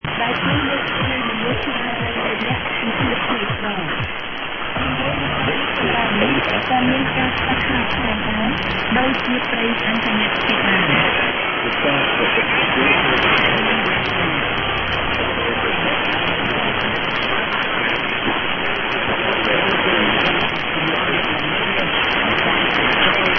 > > 1575    VOA dominant with man & woman  in SEA language 1400, "This is
> > AFN" ID in background followed by what sounded like network news